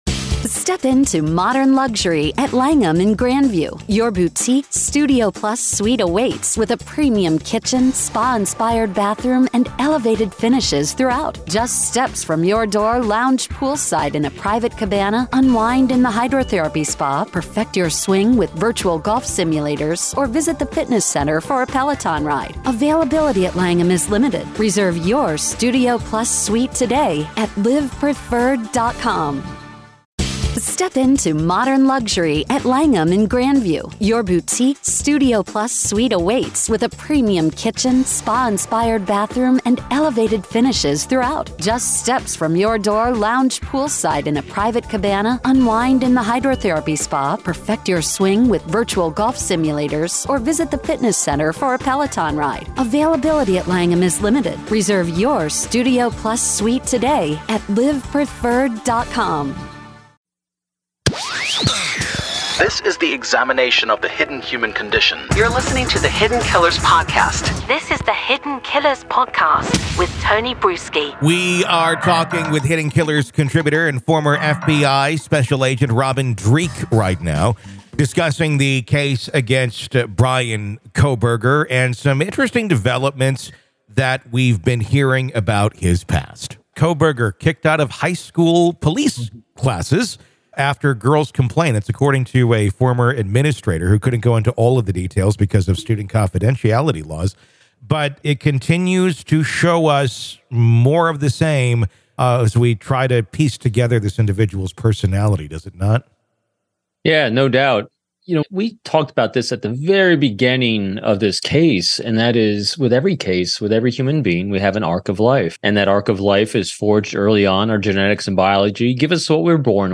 Each episode navigates through multiple stories, illuminating their details with factual reporting, expert commentary, and engaging conversation.
Expect thoughtful analysis, informed opinions, and thought-provoking discussions beyond the 24-hour news cycle.